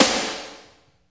45.07 SNR.wav